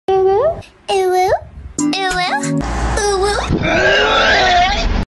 Cute Uwu Meme Sound Effect Free Download
Cute Uwu Meme